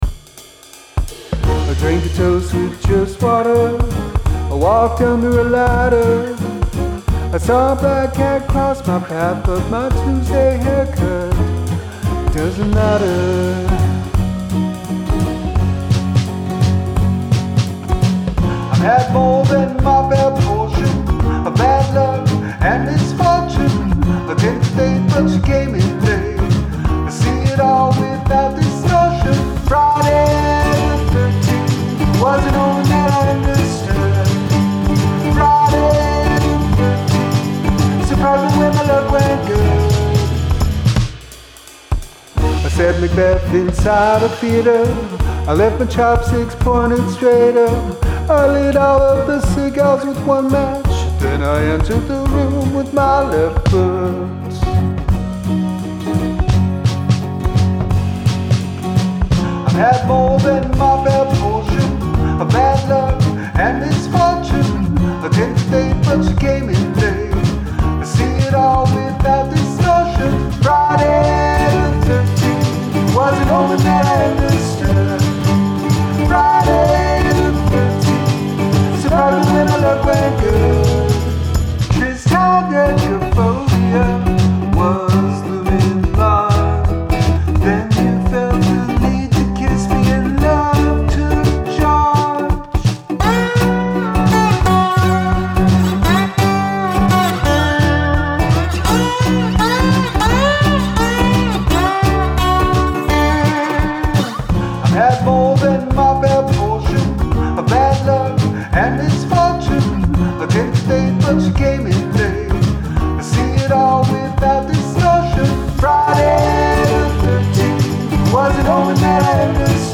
write a feel-good song with happy lyrics and upbeat music
:!: :!: :!: Echoey crooner blessings.
Suffers from really bad mastering.
Some vocal timing issues here.